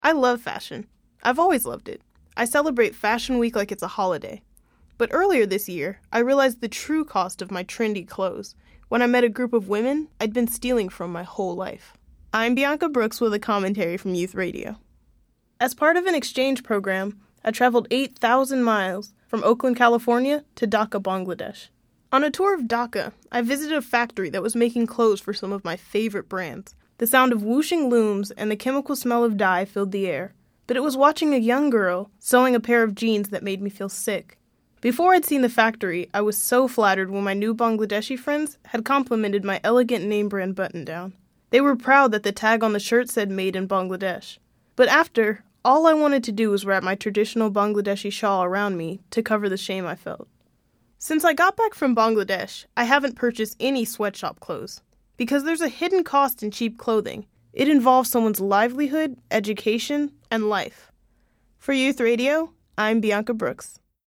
The following aired on KCBS.